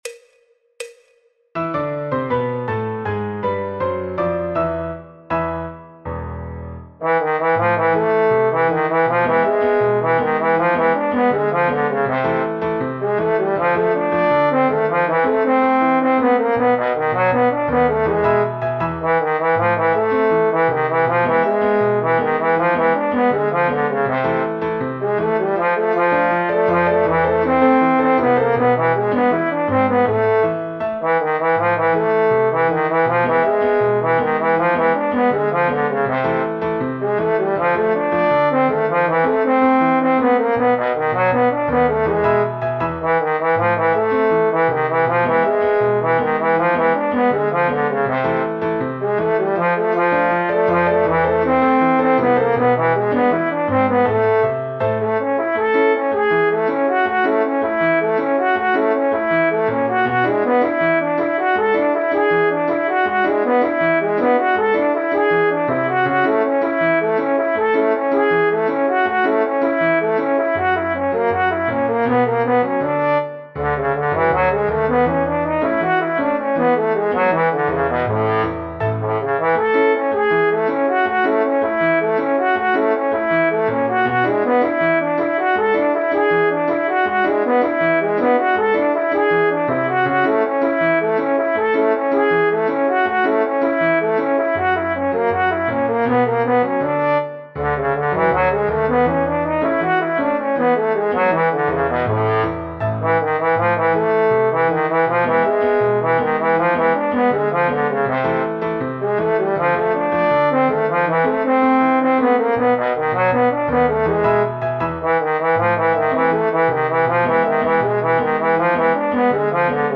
en La menor : La Mayor
Choro, Jazz, Popular/Tradicional
Trombón / Bombardino